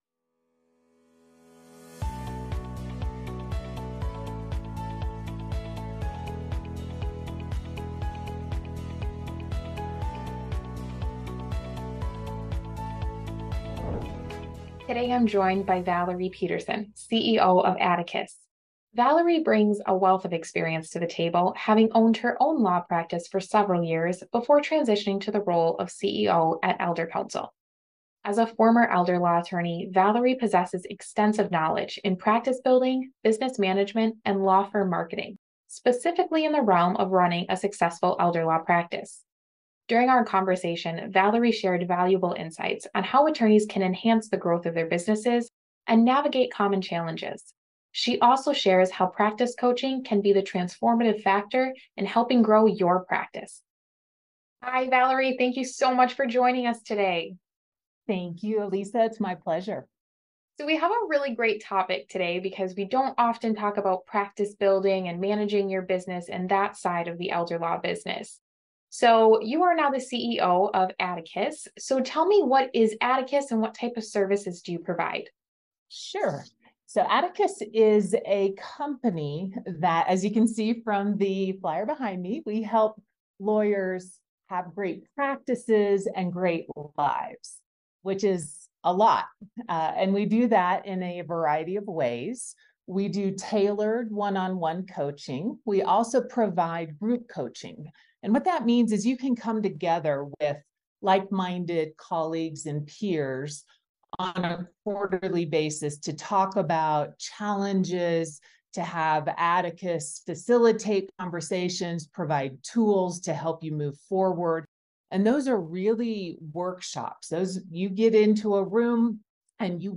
Elder Law Interview